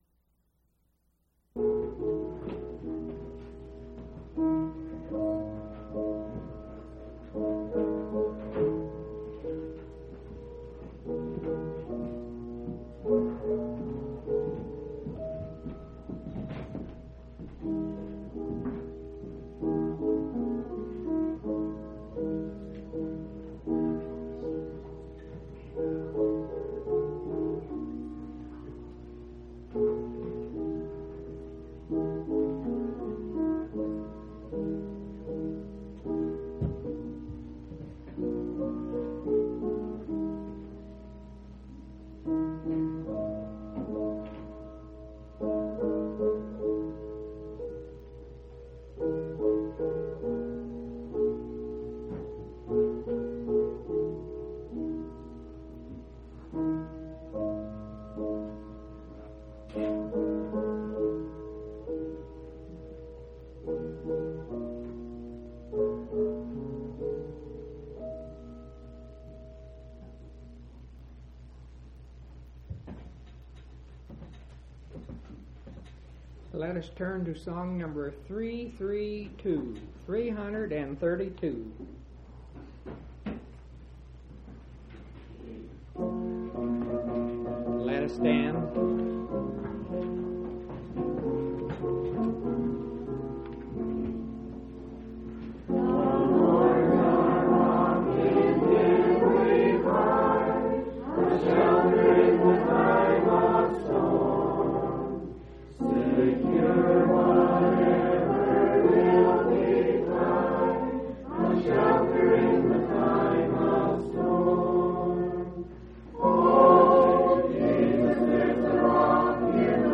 6/13/1993 Location: Colorado Reunion Event: Colorado Reunion